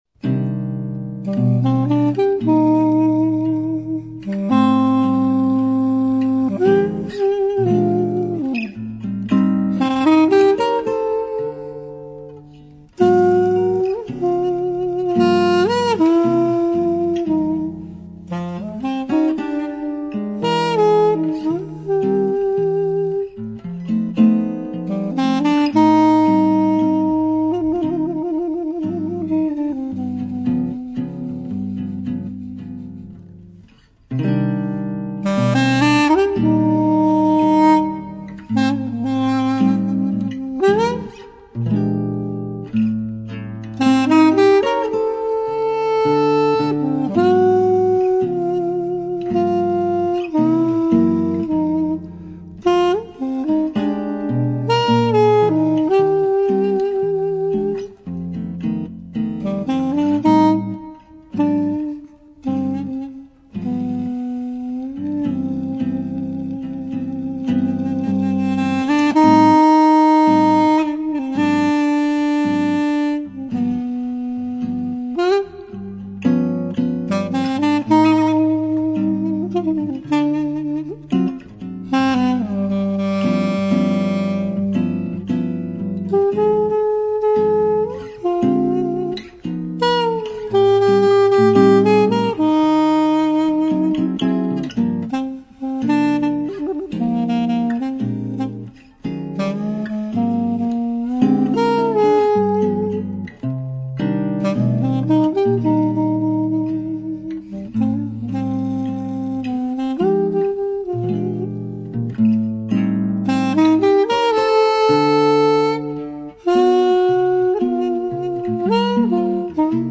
guitares
doudouk, saxo soprano